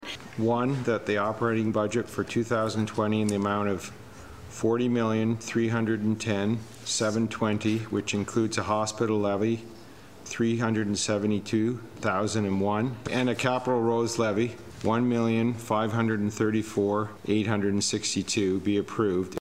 Councillor Ernie Margetson read the motion to approve the budget: